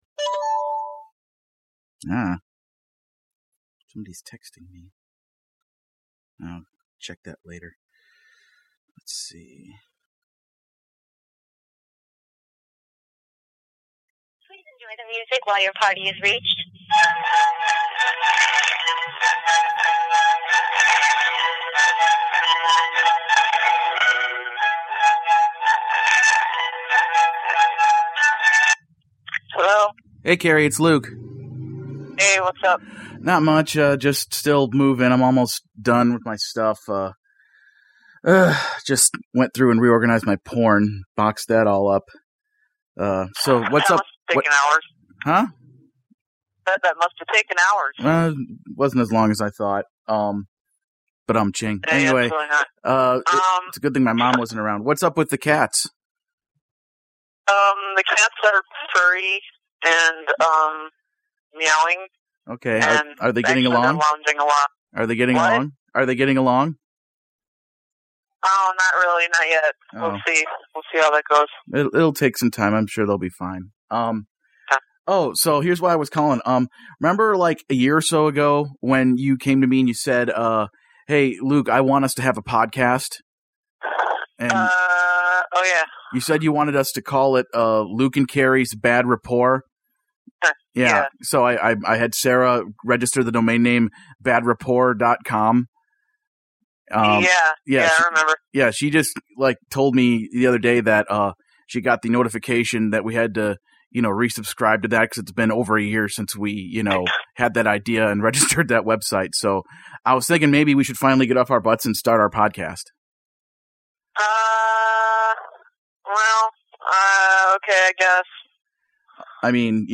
Join them as they drone on and on about whatever they feel like yapping about, as they constantly interrupt each other and pick fights with each other over silly pop-culture issues that don’t matter and no one cares about.